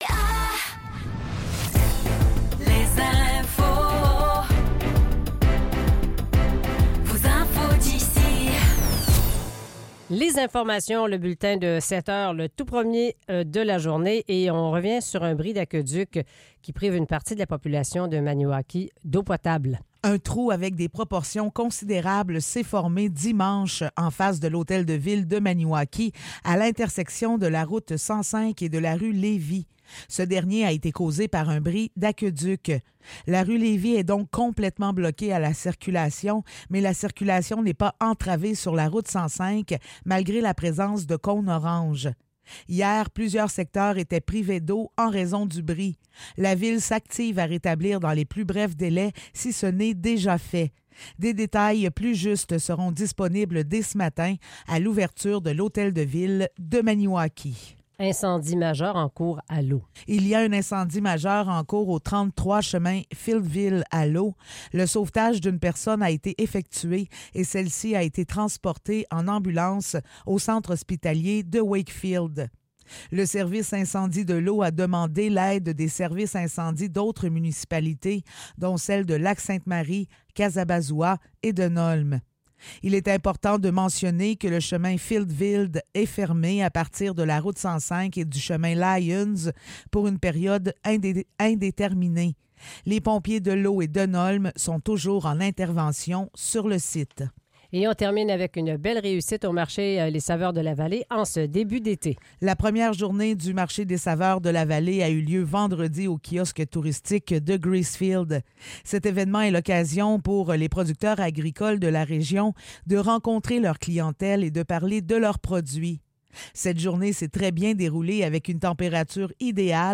Nouvelles locales - 17 juin 2024 - 7 h